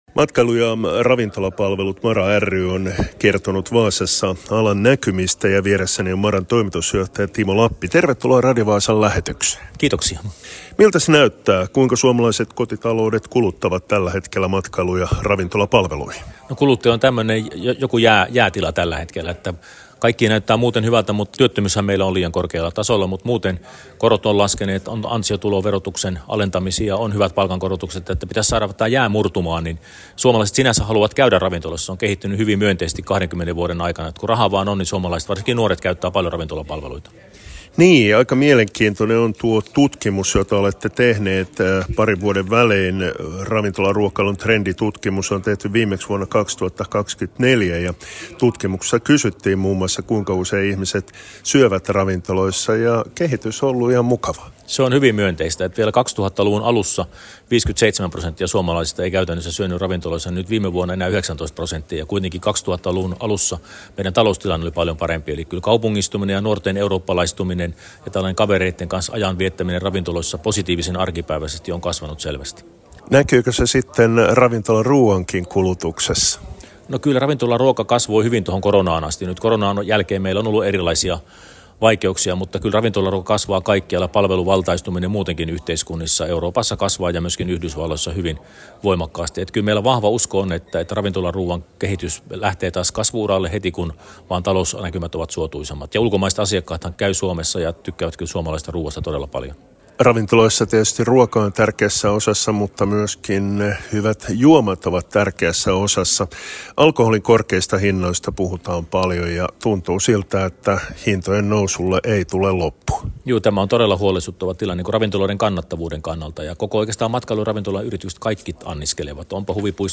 Matkailu- ja Ravintolapalvelut MaRa ry:n liittokokoustapahtuma pidettiin Vaasassa 4.11.2025.